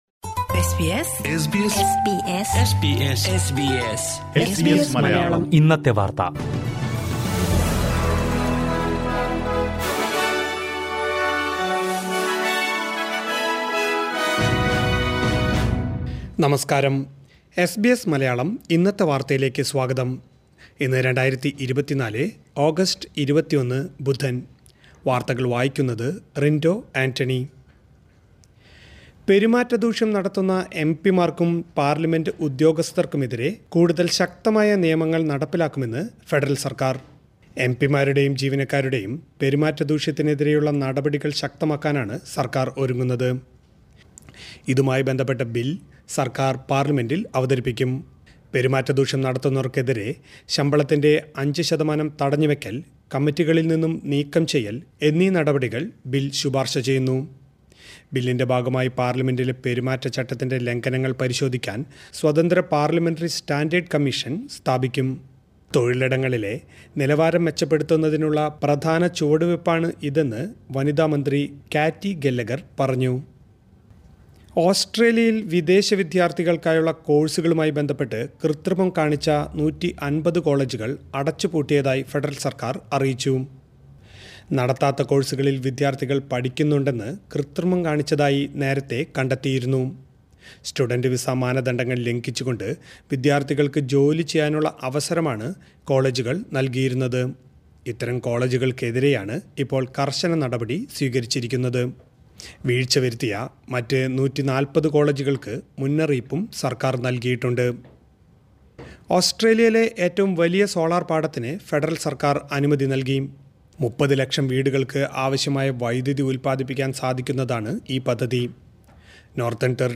2024 ഓഗസ്റ്റ് 21ലെ ഓസ്‌ട്രേലിയയിലെ ഏറ്റവും പ്രധാന വാര്‍ത്തകള്‍ കേള്‍ക്കാം.